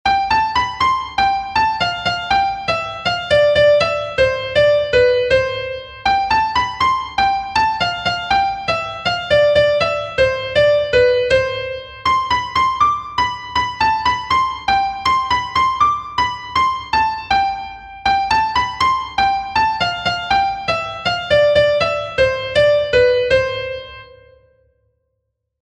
Kontakizunezkoa
Zortziko handia (hg) / Lau puntuko handia (ip)
A-A-B-A